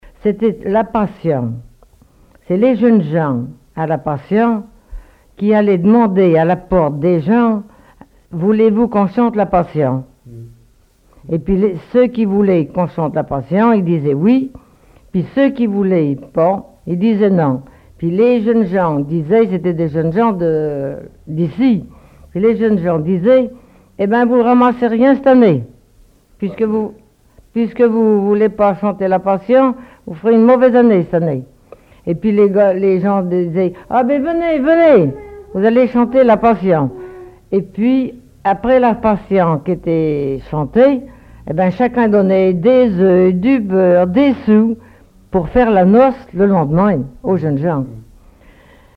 collecte en Vendée
Catégorie Témoignage